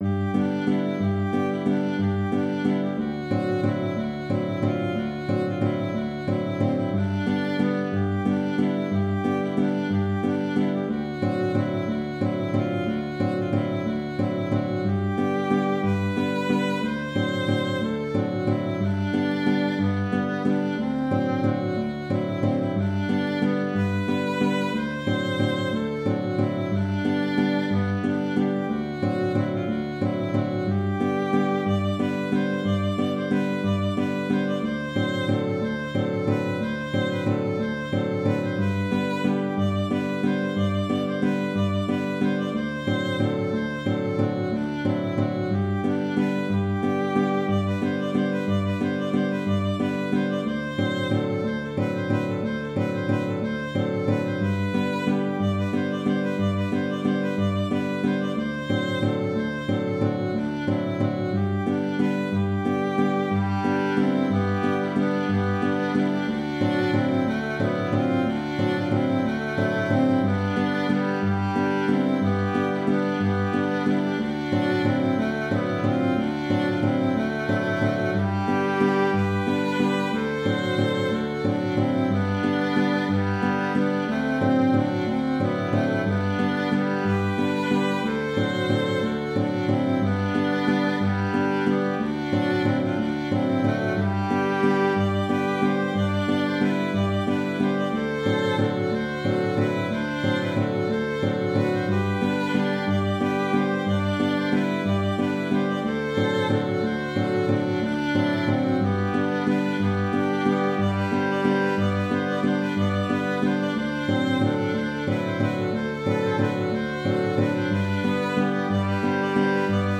Valse
J’ai composé cette valse en pensant aux guinguettes des bords de l’Oise, ou d’ailleurs. Je la trouvais un peu ringarde avec ses deux accords seulement, mais elle a plu à mes ami(e)s musicien(ne)s et je l’ai enrichie de deux contre-chants. Le premier est à priori plutôt destiné à un violon, le second à une flûte.
Dans le fichier audio, le thème est joué une première fois sans deuxième voix, puis les deux contrechants se succèdent